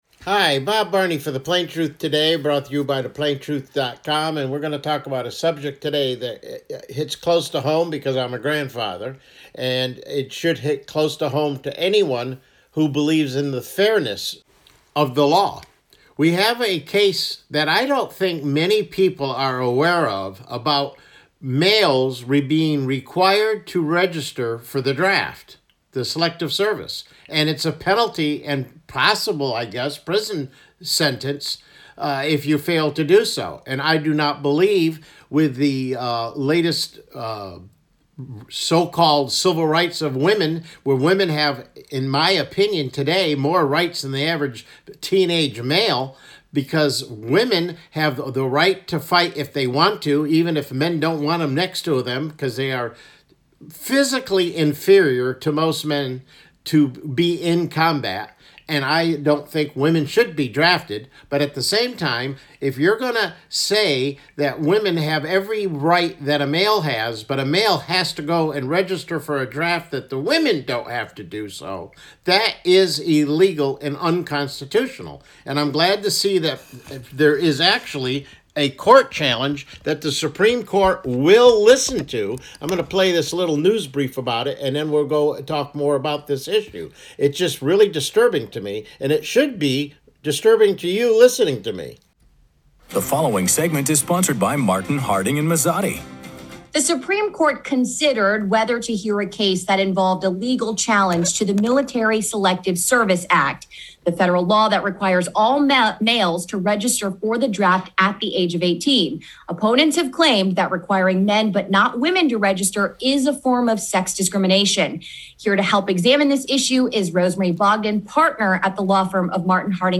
CLICK HERE TO LISTEN TO THE PLAIN TRUTH TODAY MIDDAY BROADCAST: Selective Service Registration AKA The Coming Draft